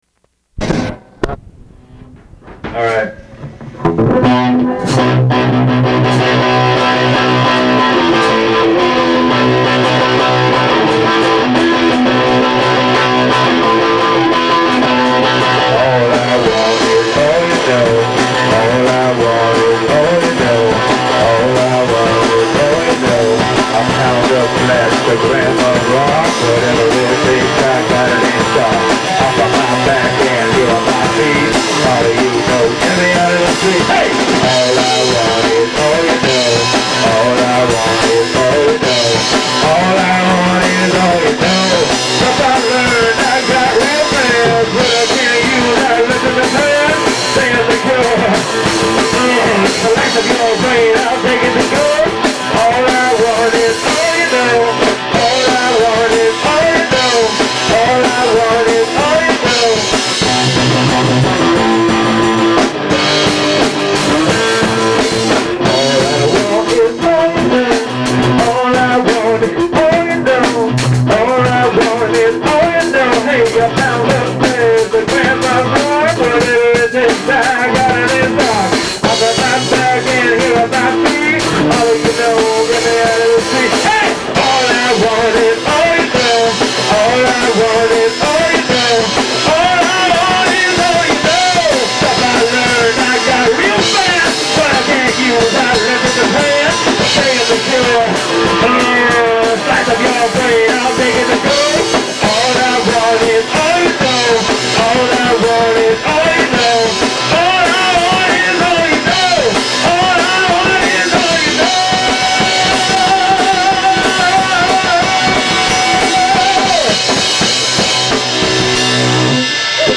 recorded in a Los Angeles garage